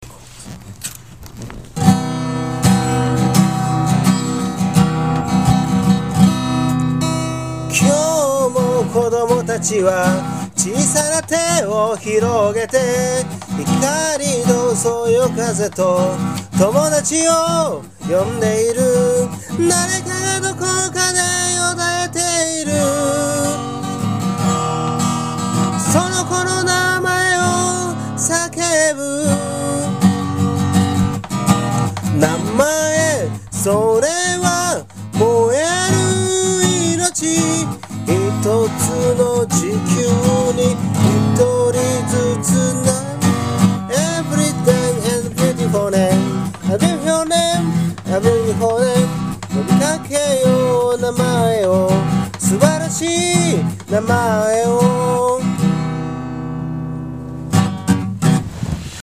この唄を歌った。